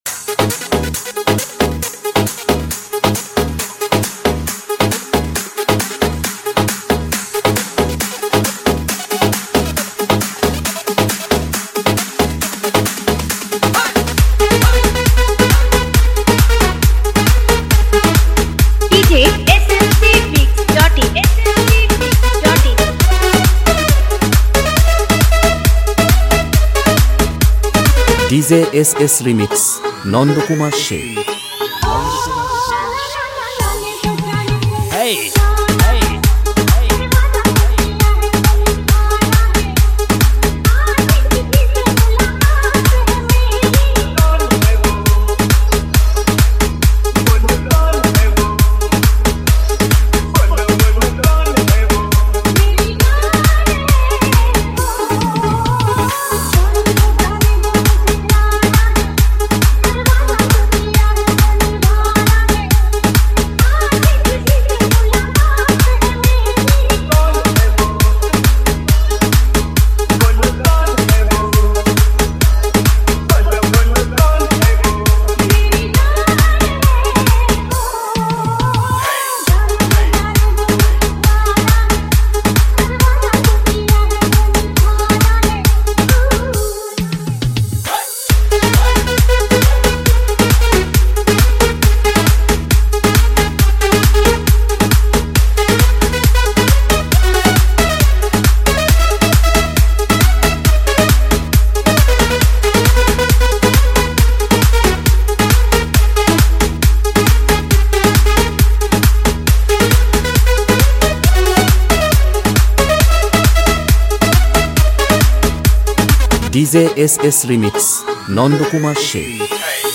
EDM Dance Mix